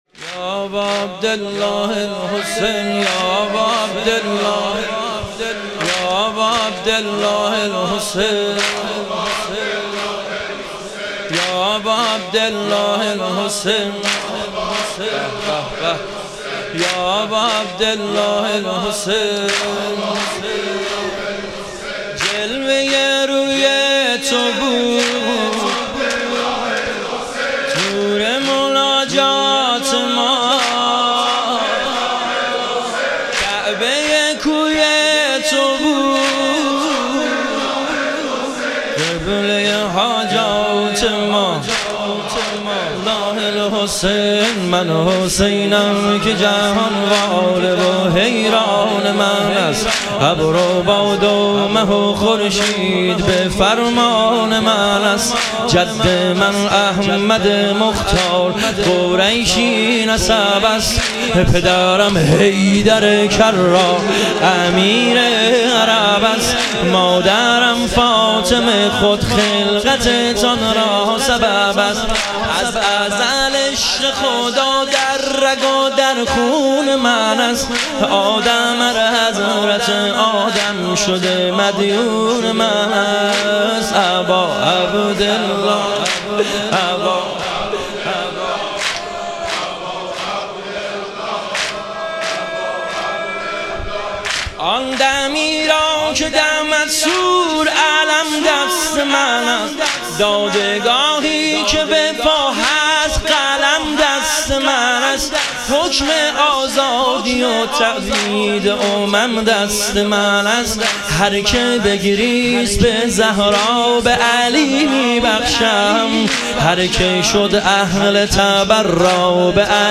شهادت امام کاظم علیه السلام - واحد